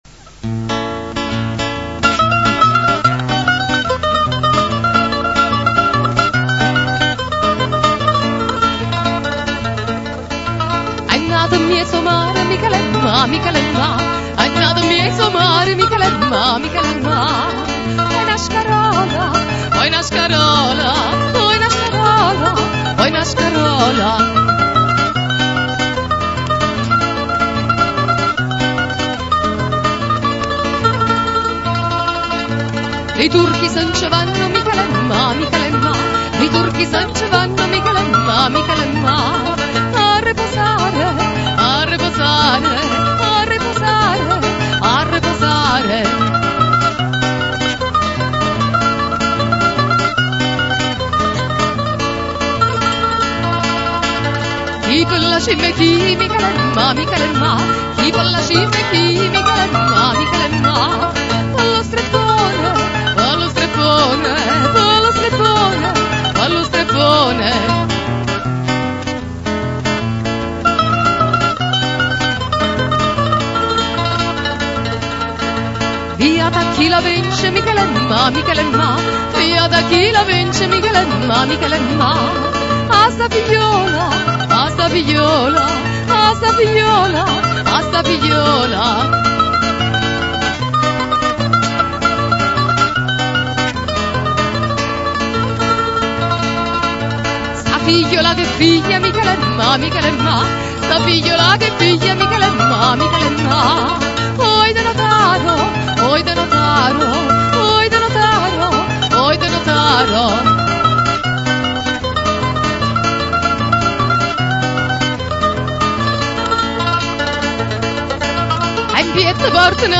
Ascolta Classiche vesuviane cantate da " Napoli Antica" Se non si ascolta subito il suono attendere qualche secondo, solo la prima volta